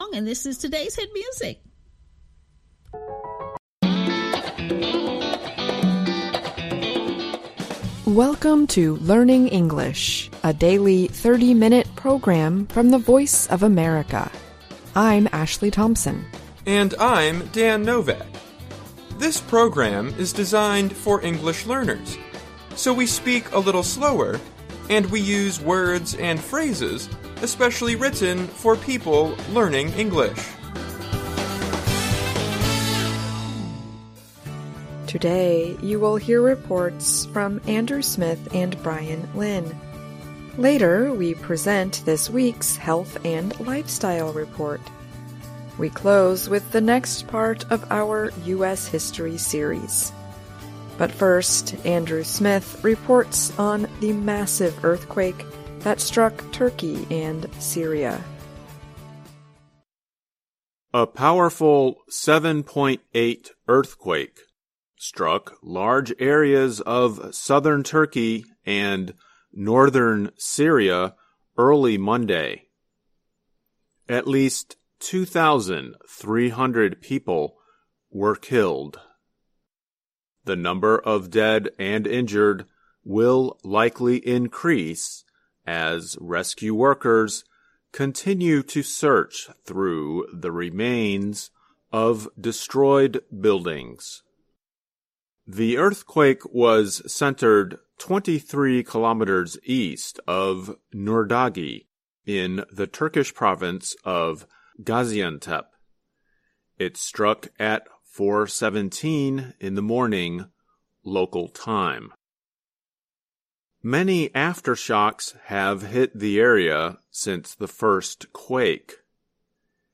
Learning English use a limited vocabulary and are read at a slower pace than VOA's other English broadcasts.